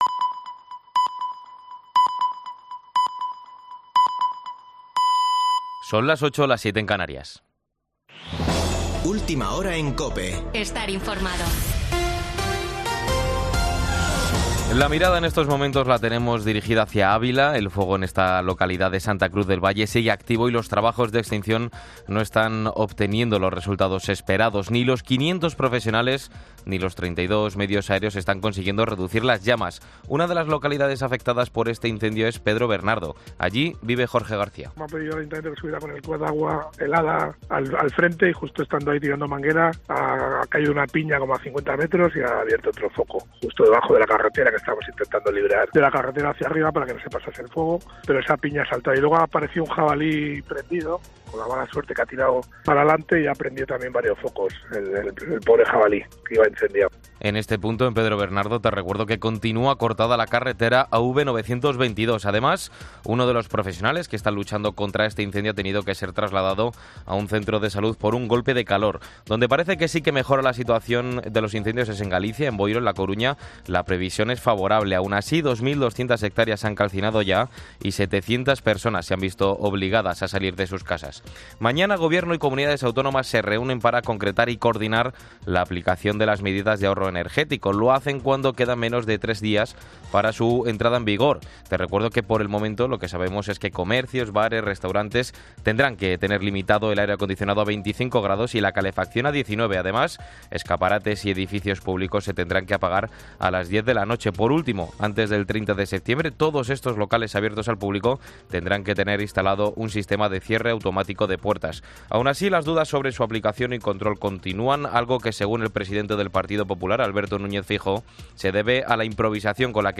Boletín de noticias de COPE del 7 de agosto de 2022 a las 20.00 horas